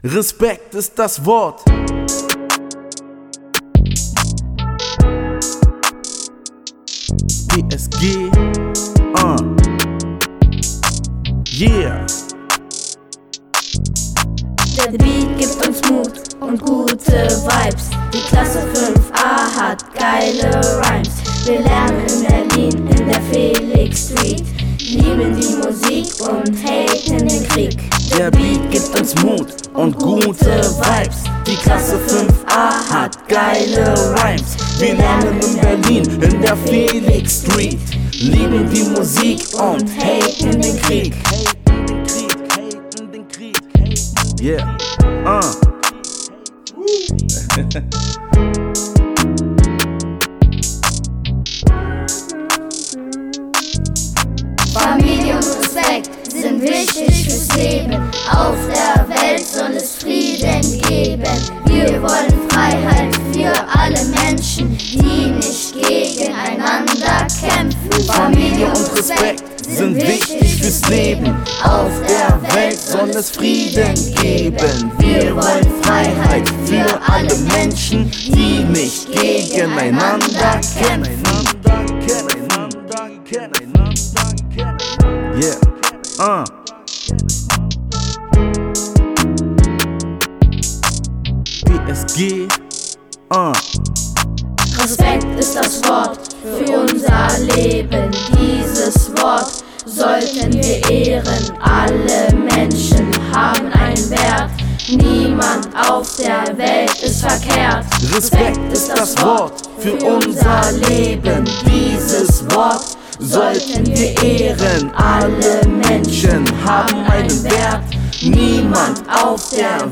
Beat zum Respekt ist das Wort Workshop der 5.